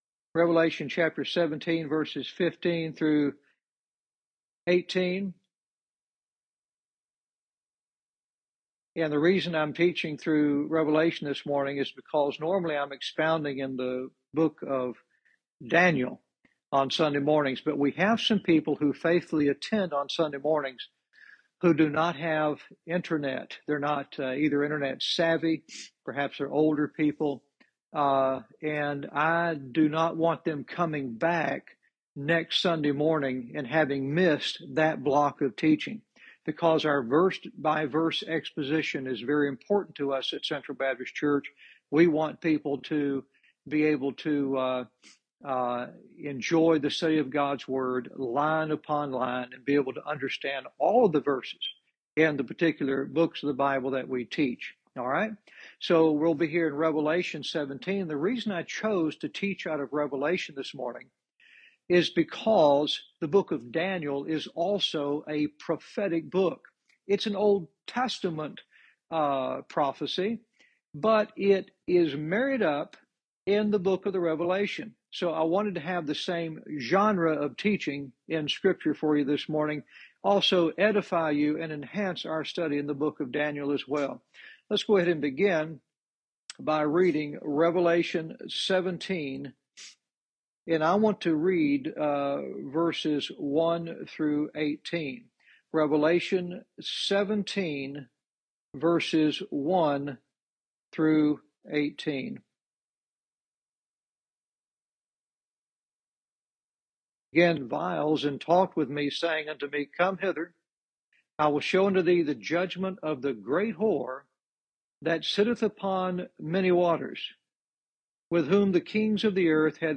Verse by verse teaching - Revelation 17:15-18